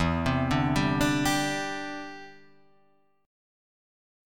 Em7#5 Chord